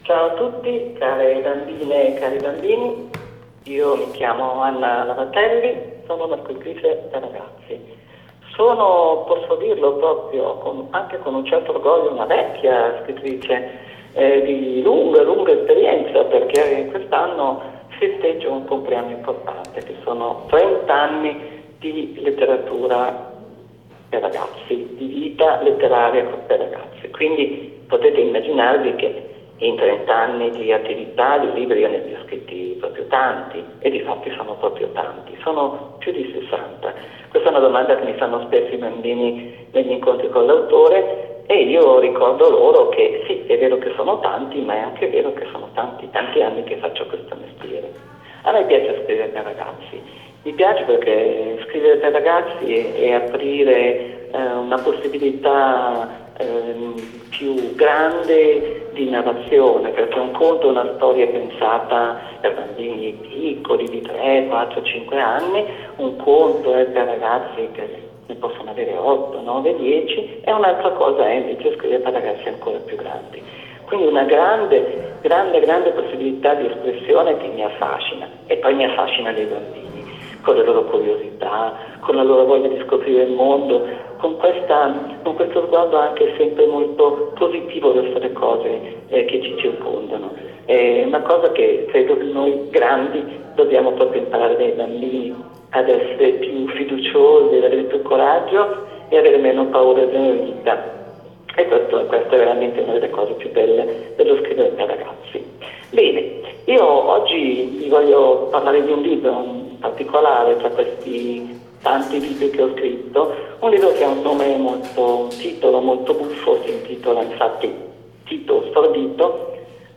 Lettura